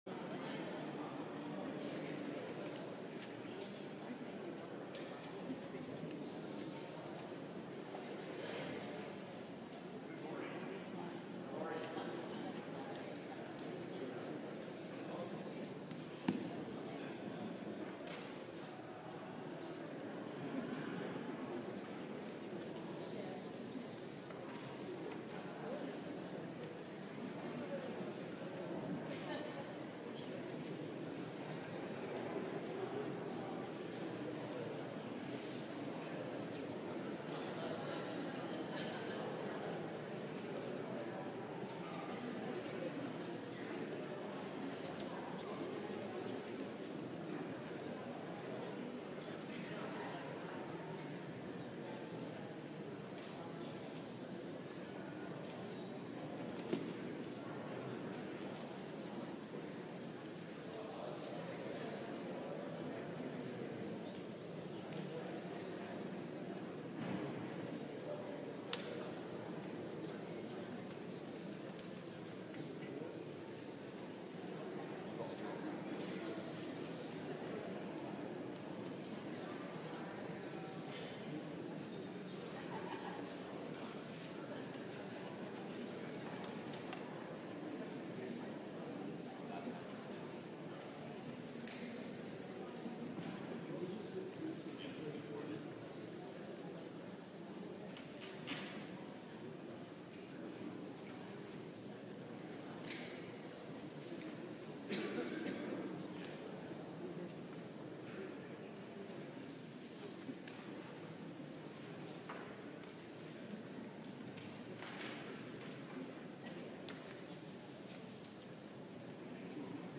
LIVE Morning Worship Service - Jesus the Foundation
Congregational singing—of both traditional hymns and newer ones—is typically supported by our pipe organ.